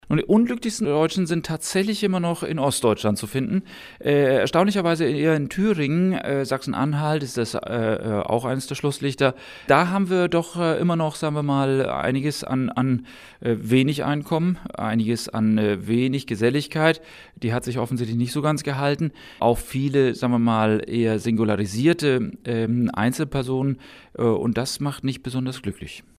O-Ton: Glücksatlas